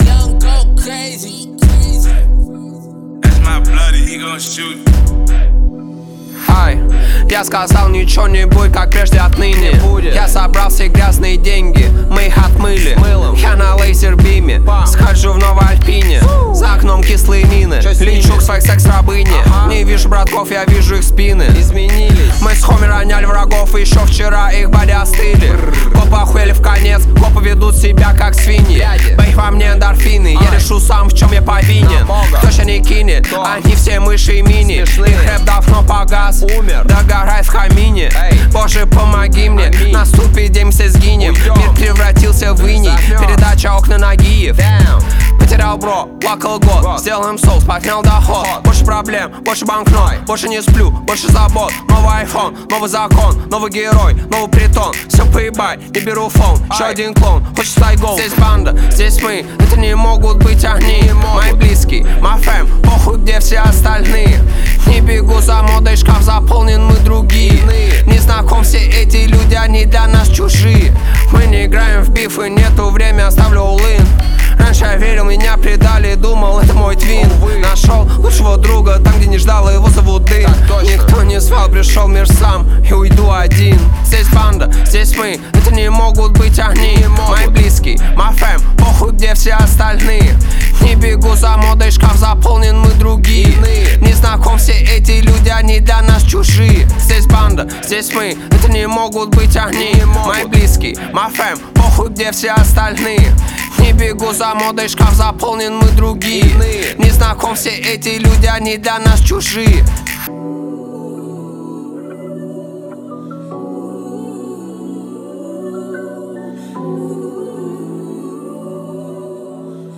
Рэп, Хип-хоп